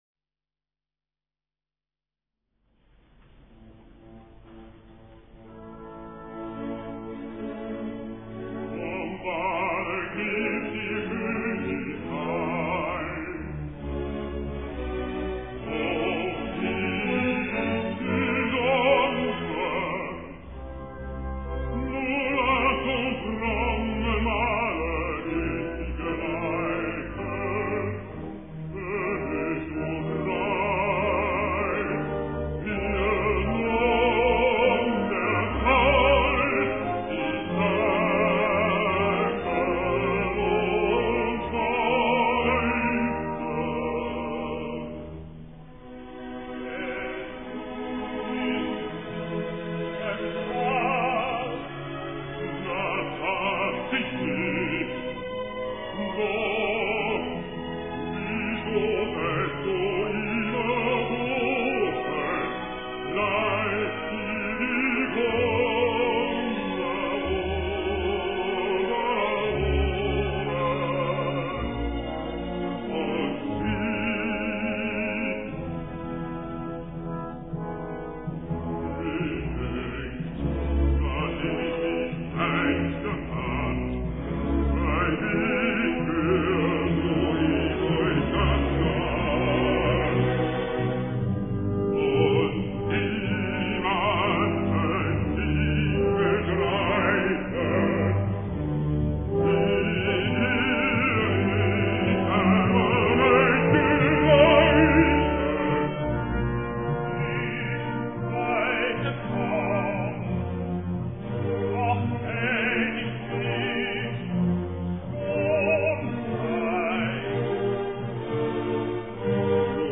Parsifal: Verwandlungsmusik (Музыка перемен (1. Часть), 1. Акт). Байрёйт 1962. Дир. Ханс Кнаппертсбуш. 2.11 мин (384 КБ)